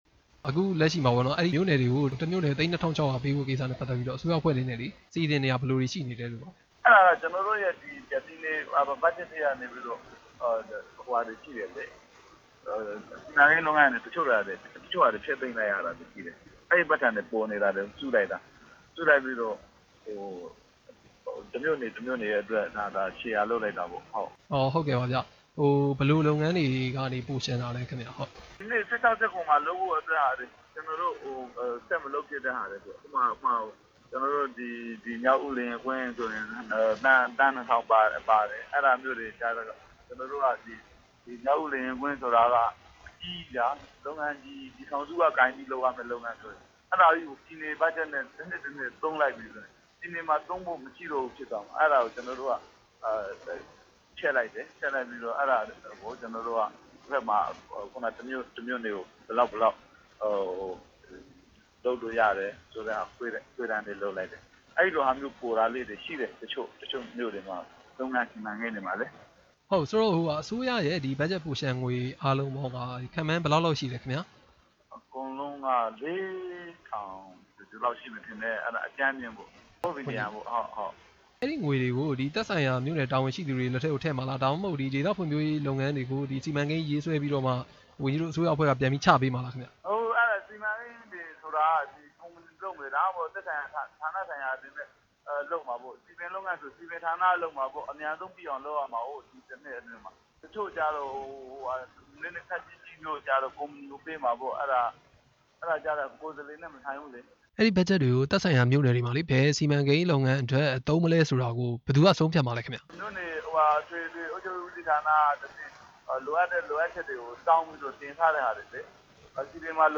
ပင်လုံမှာကျင်းပမယ့် ပြည်ထောင်စုနေ့ ကြိုတင်ပြင်ဆင်မှု မေးမြန်းချက်